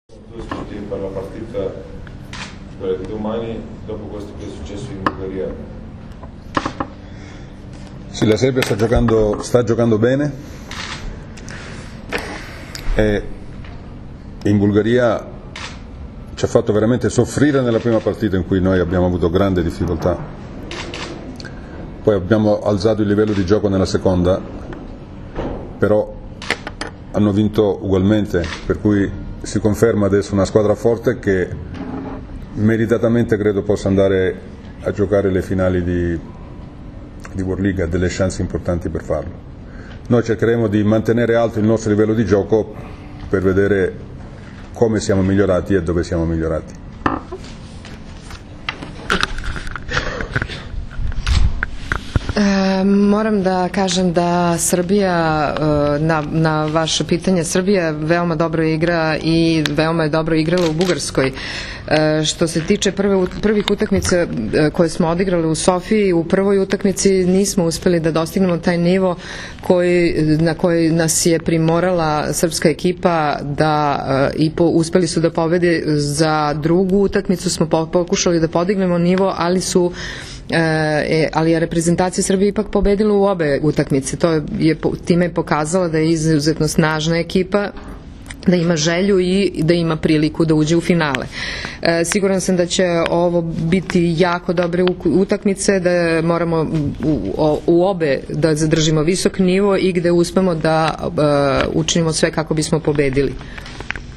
Tim povodom, danas je hotelu “Sole mio” u Novom sadu održana konferencija za novinare, kojoj su prisustvovali Dragan Stanković, Todor Aleksijev, Igor Kolaković i Kamilo Plaći, kapiteni i treneri Srbije i Bugarske.
IZJAVA KAMILA PLAĆIJA